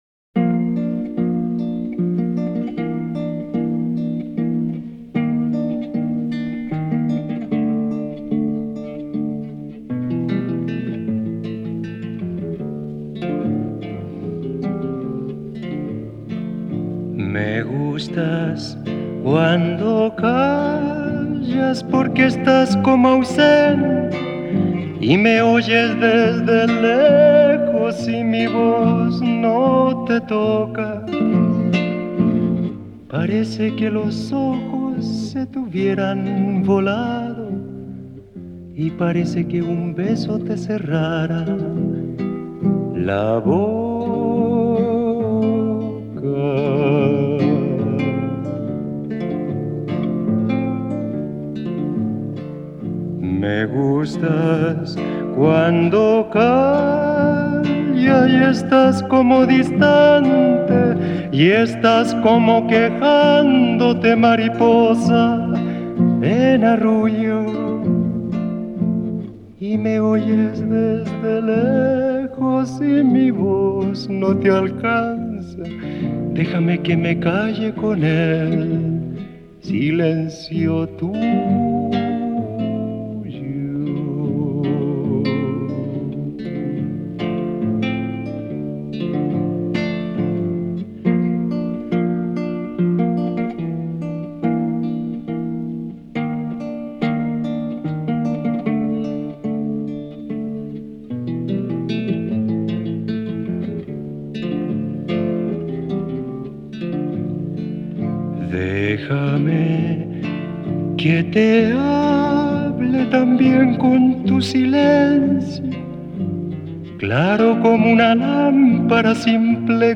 ESTILO: Cantautor